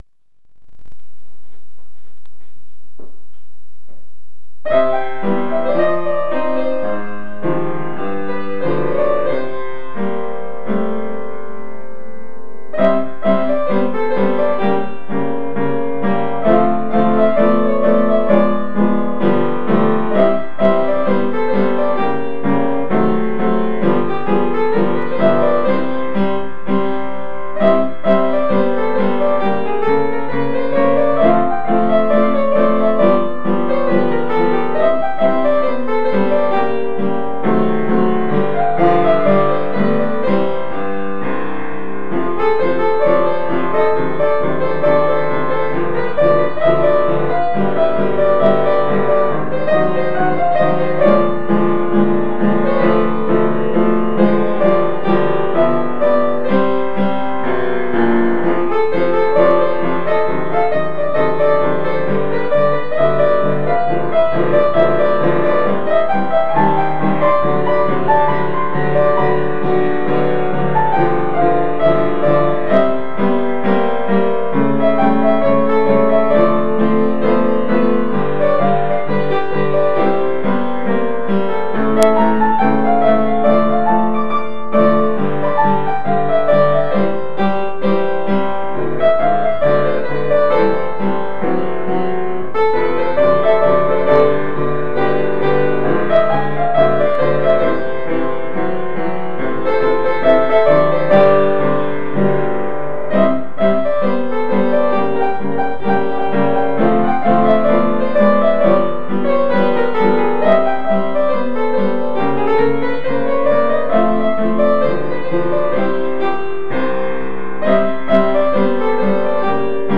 אך ההקלטה צורמת בעליל!